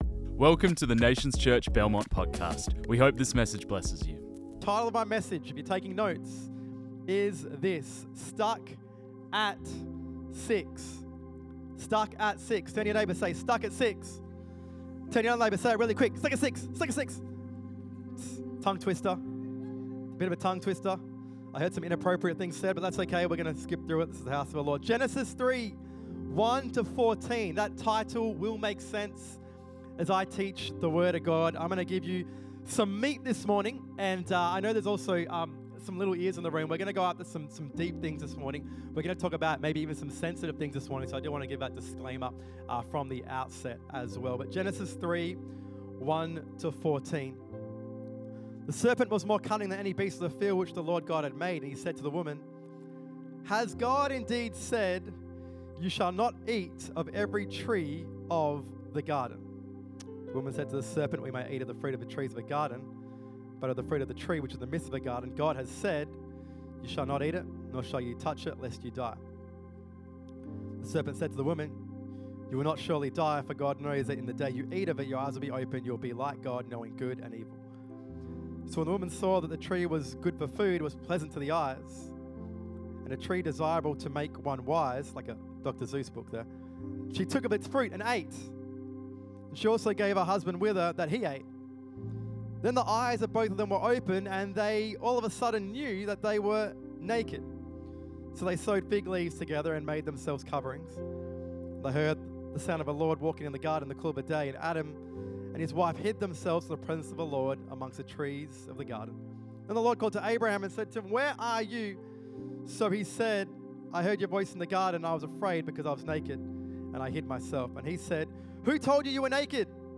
This message was preached on 03 August 2025.